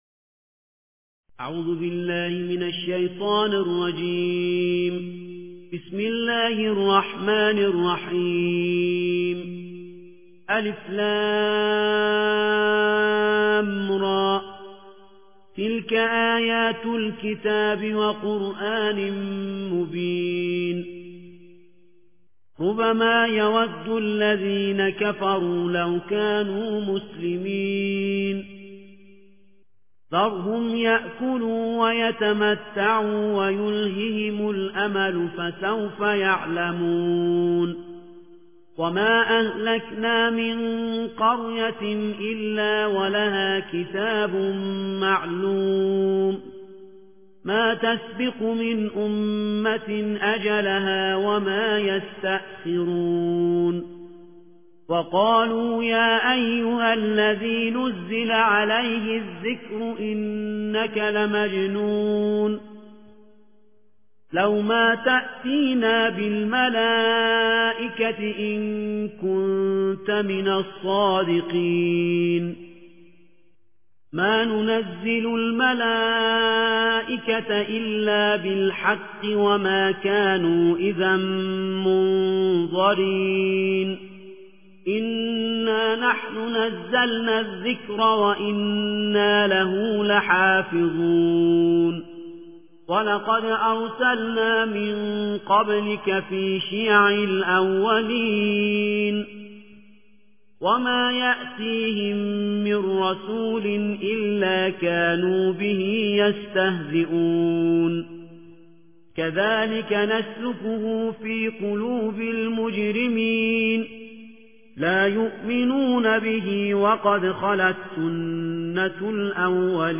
ترتیل جزء چهاردهم قرآن